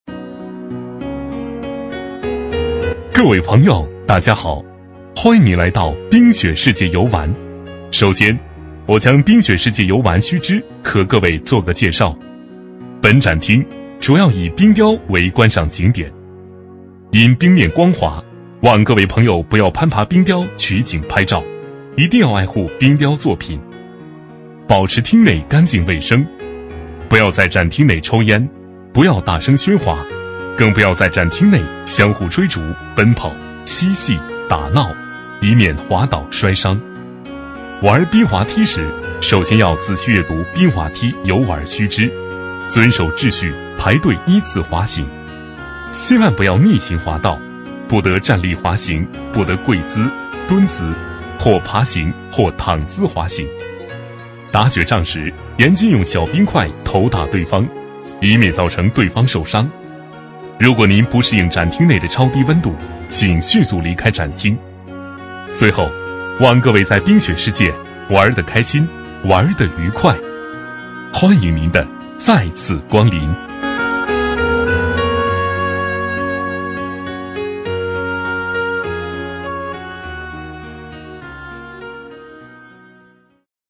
B类男01
【男1号抒情】冰雪世界介绍